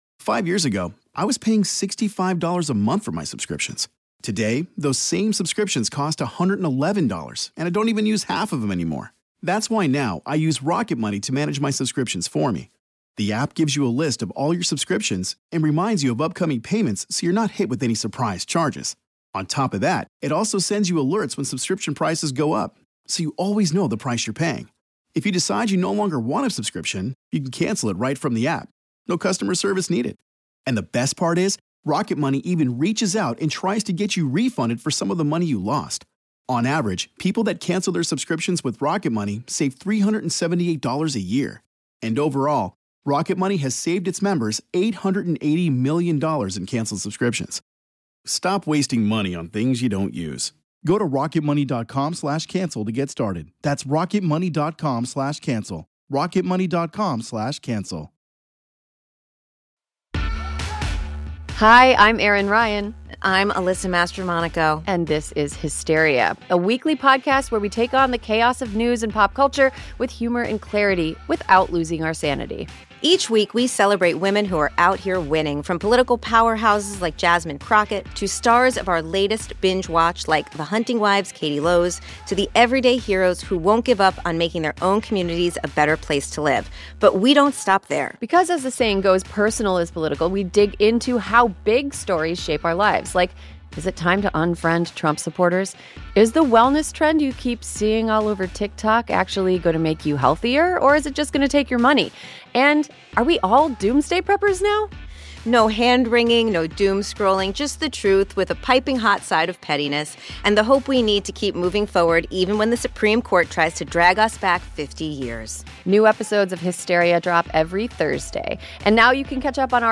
a bicoastal crew of hilarious and super smart ladies